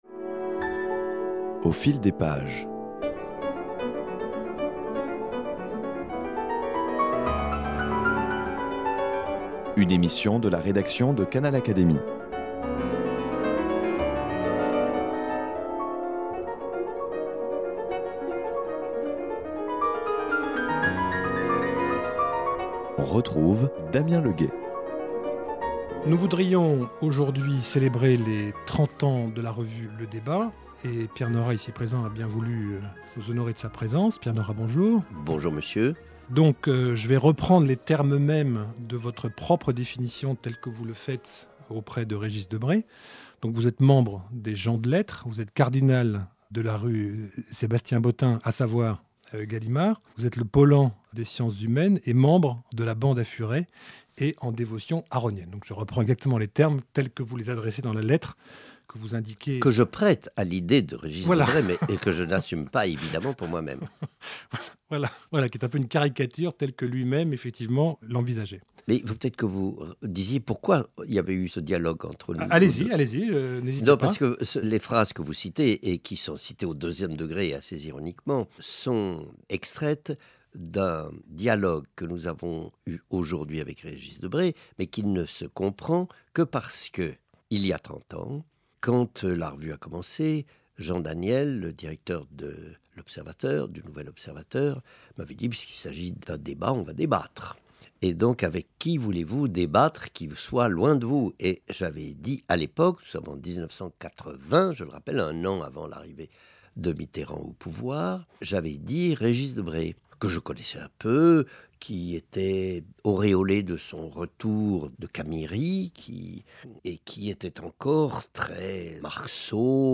Dans cet entretien il nous dresse le portrait du paysage intellectuel français en 1980 (après l’effondrement du mur de Berlin et juste avant l’arrivée de la gauche au pouvoir), nous rel